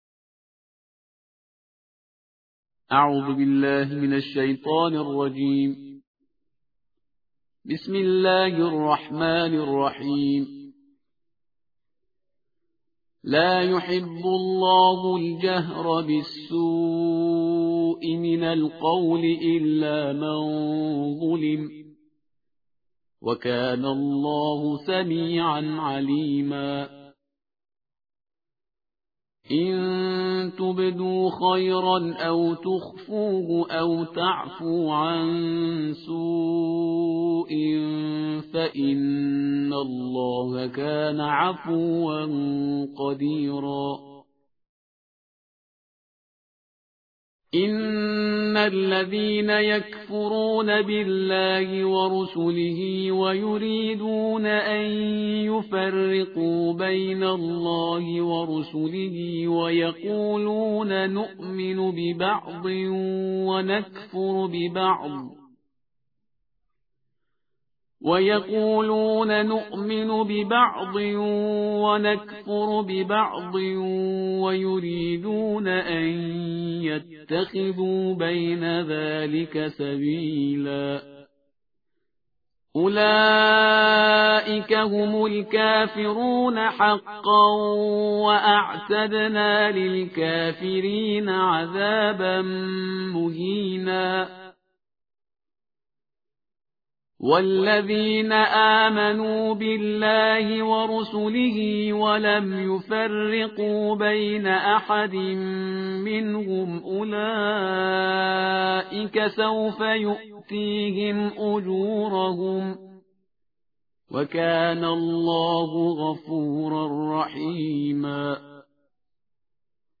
تلاوت ترتیل جزء ششم کلام وحی با صدای استاد